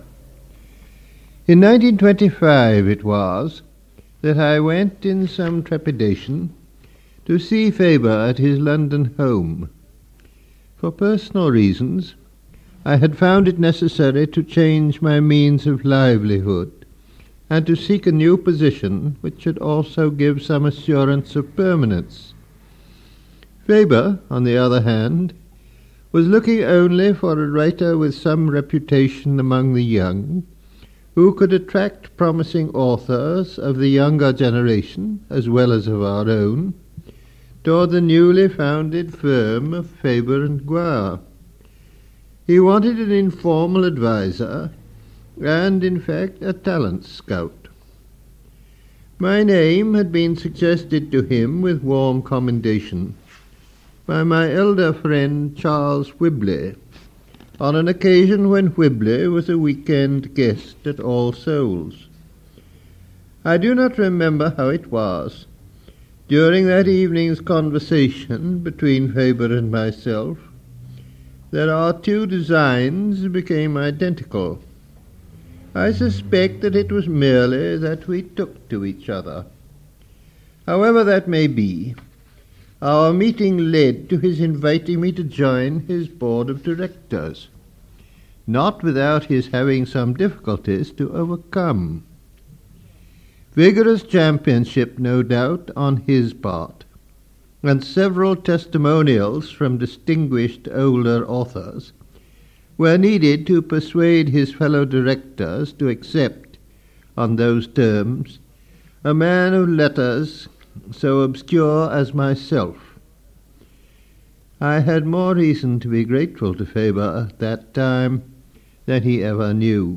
Clip-of-TSE-reading-GCF-memorial-address.mp3